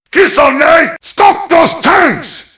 mvm_tank_alerts09.mp3